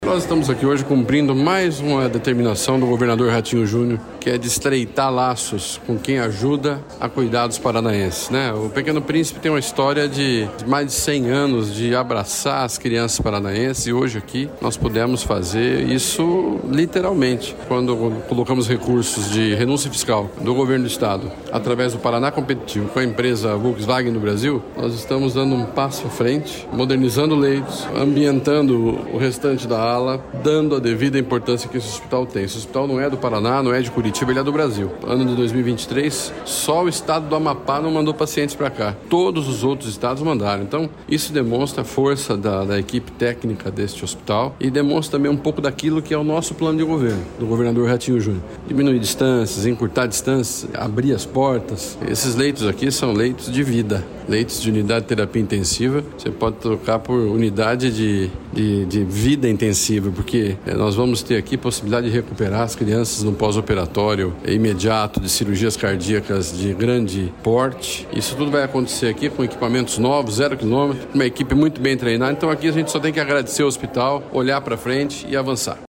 Sonora do secretário da Saúde, Beto Preto, sobre a abertura de novos leitos de UTI no Hospital Pequeno Príncipe